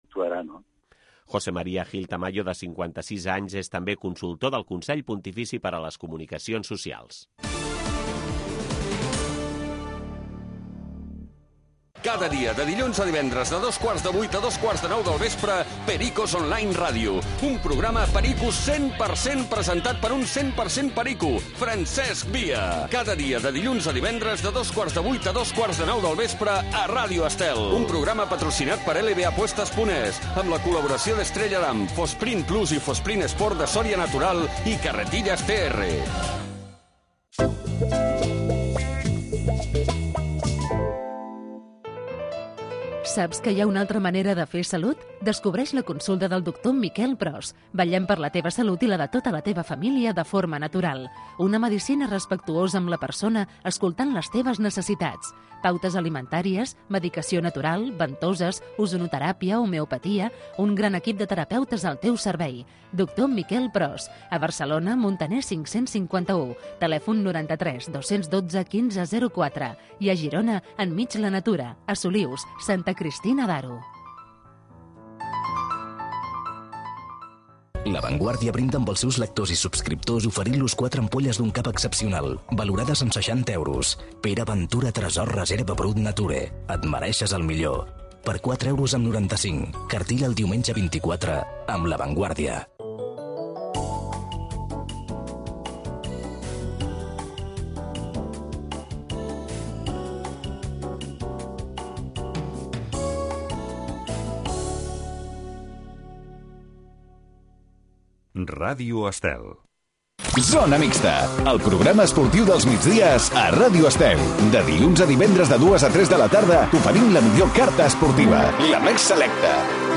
Zona mixta. Programa diari dedicat al món de l'esport. Entrevistes amb els protagonistes de l'actualitat poliesportiva.